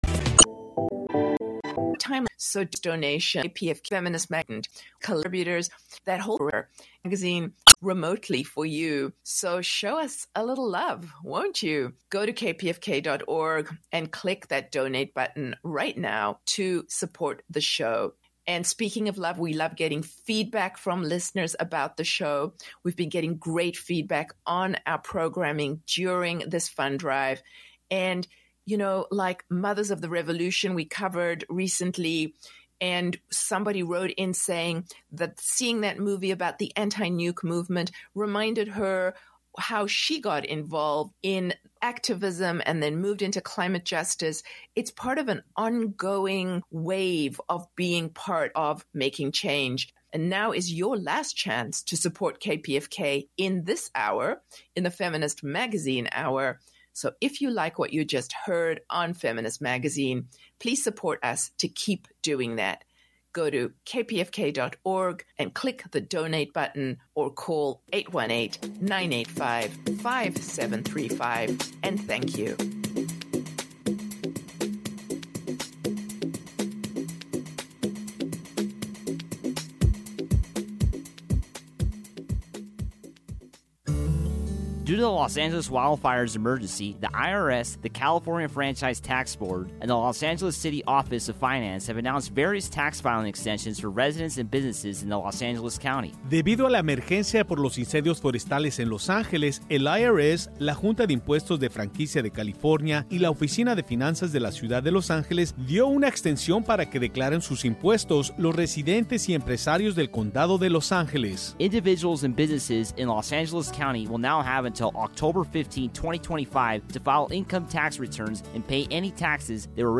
Feminist Magazine is the weekly Southern California radio show with intersectional feminist perspectives.